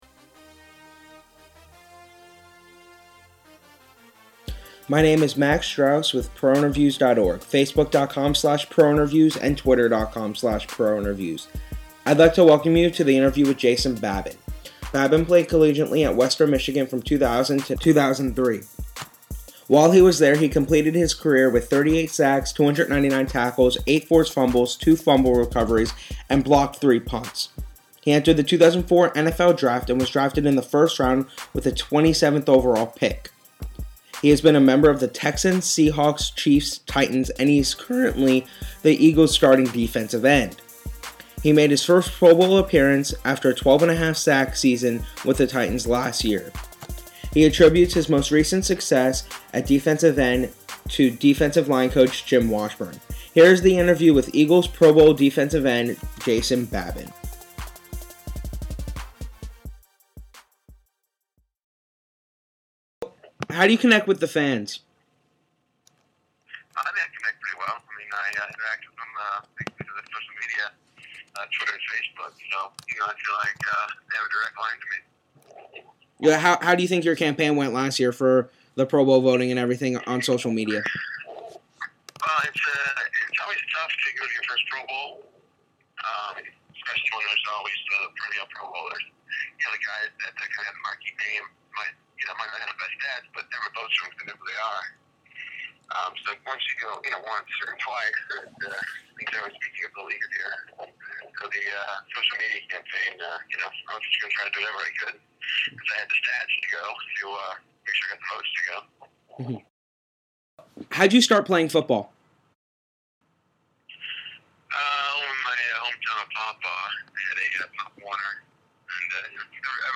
This interview was conducted in the middle of the 2011 NFL season.
interview-with-jason-babin.mp3